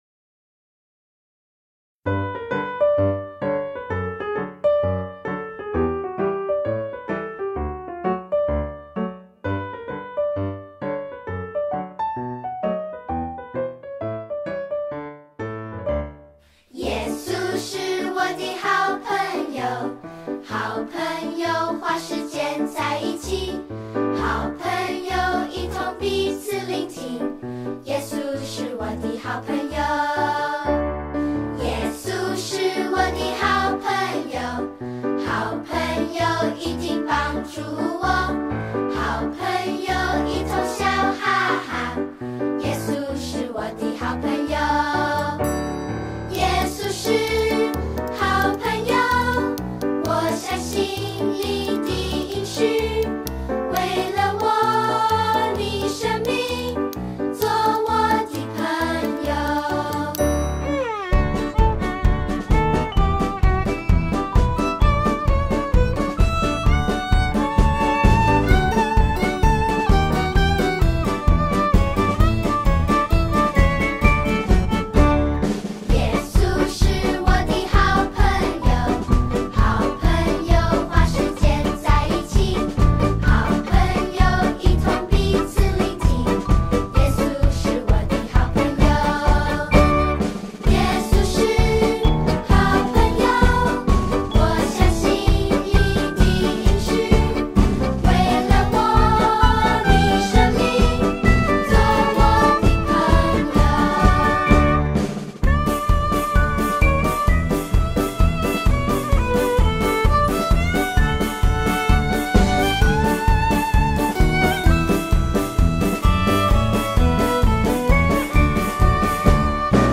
动作跟唱 | 耶稣是我的好朋友 (视频+音频)